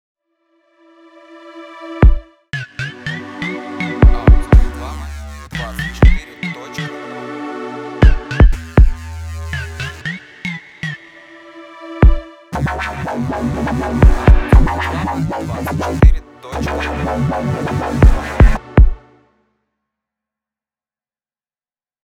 Джингл для заставки